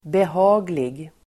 Uttal: [beh'a:glig]